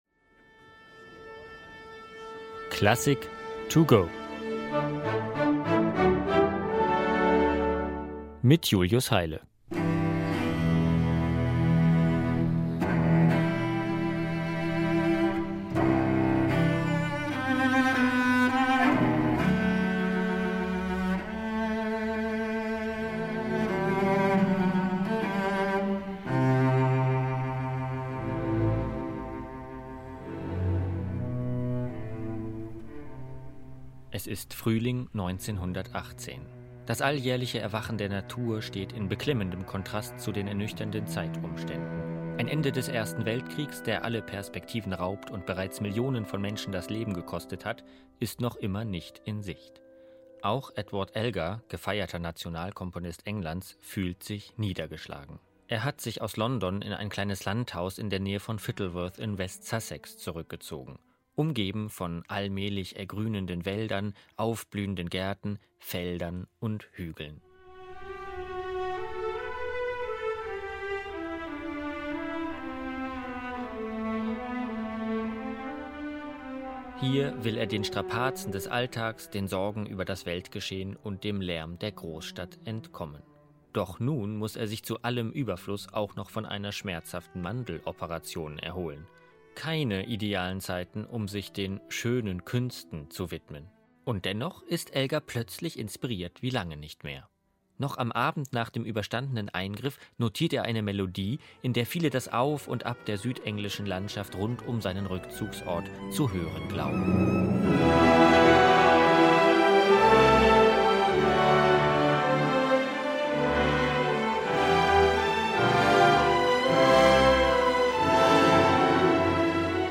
in einer kurzen Werkeinführung für unterwegs über Edward Elgars